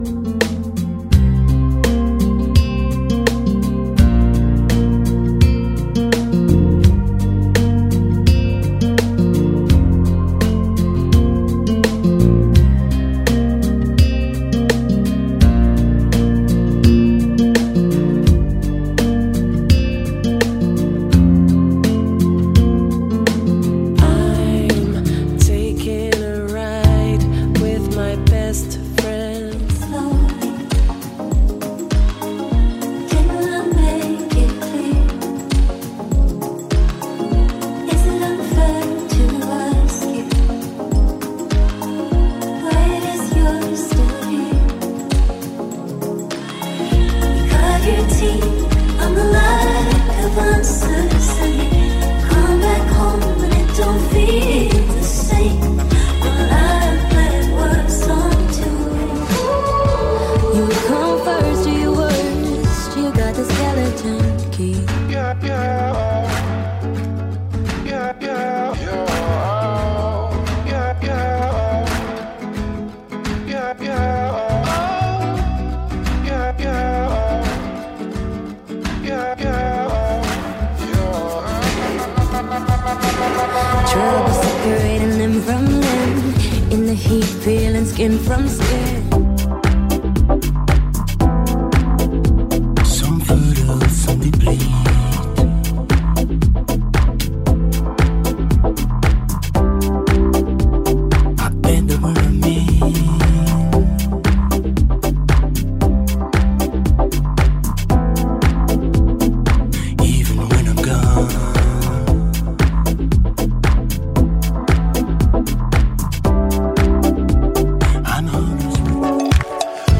Country Hits from Popular Artists